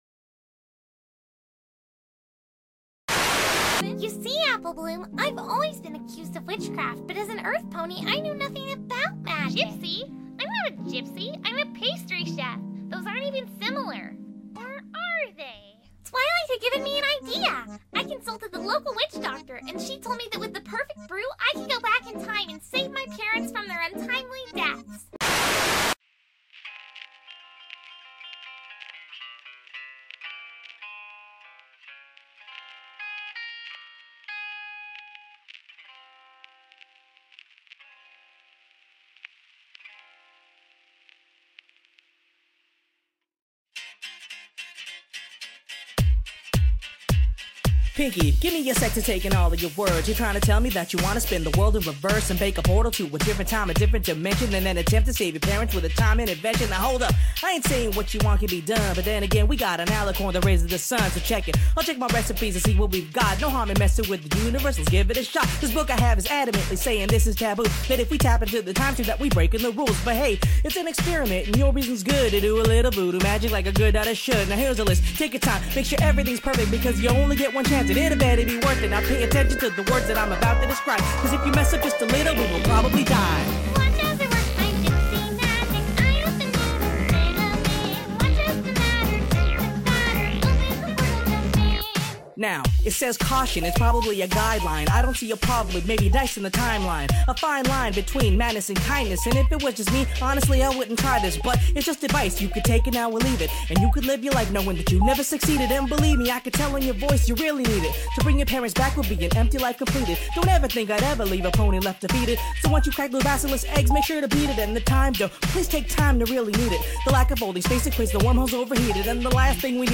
Hip-Hop Remix of the Russian Gypsy Jazz Remix